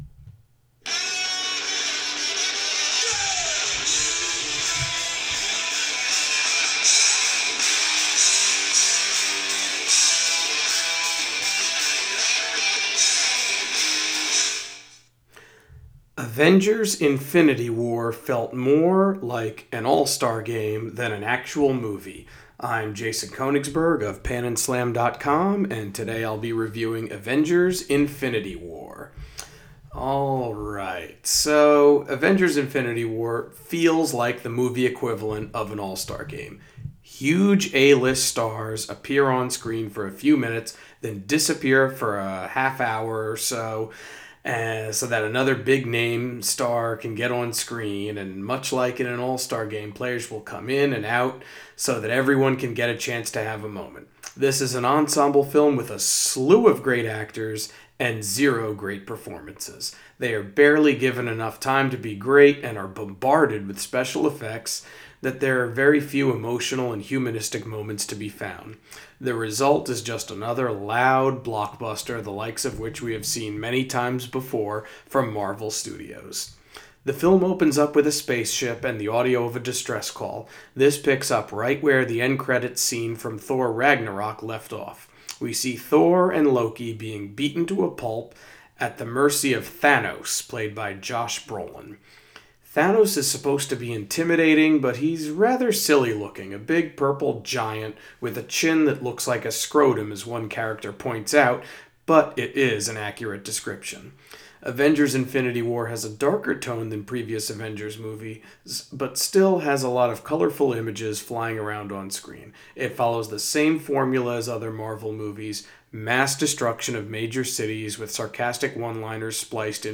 Movie Review: Avengers: Infinity War